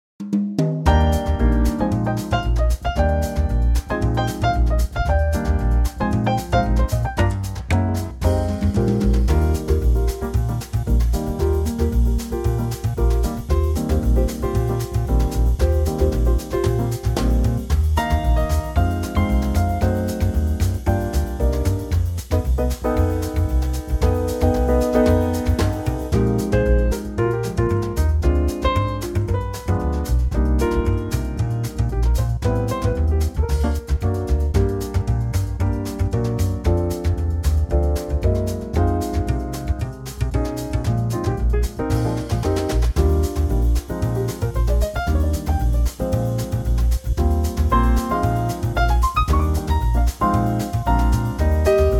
key - Eb - vocal range - G to Bb (optional top C)
Superb Latin Trio arrangement
-Unique Backing Track Downloads